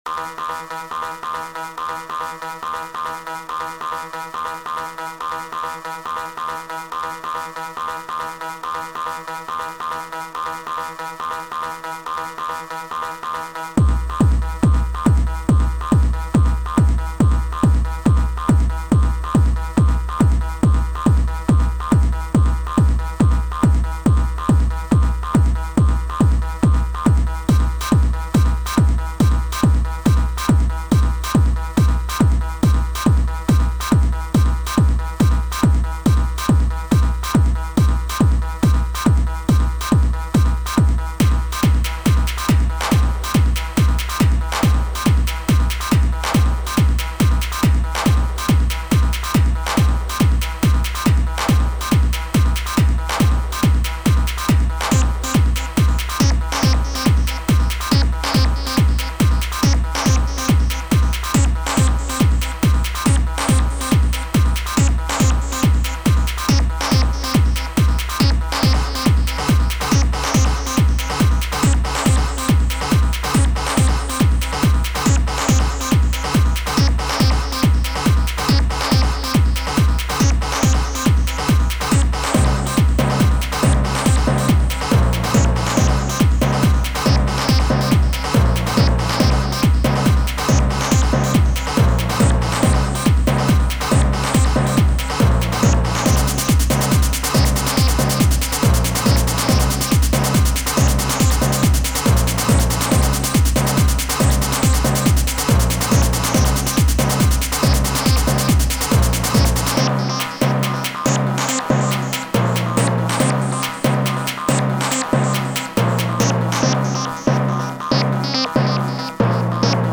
techno-progressive